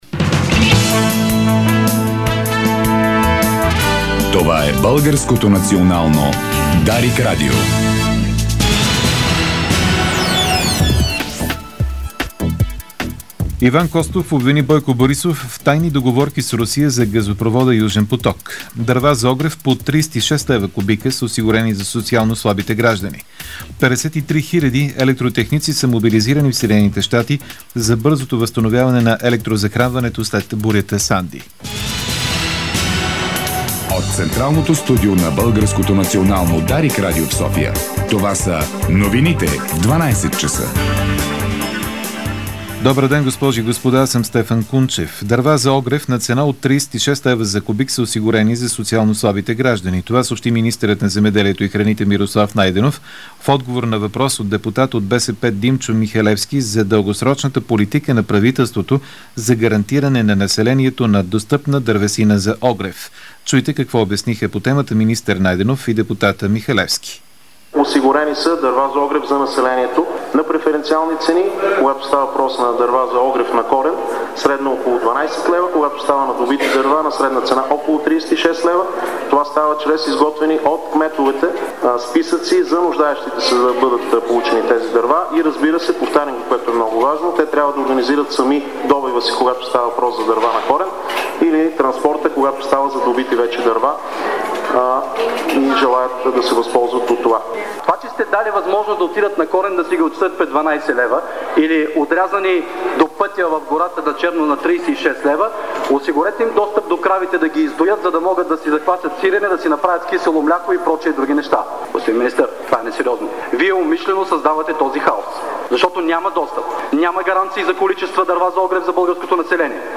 Обедна информационна емисия - 02.11.2012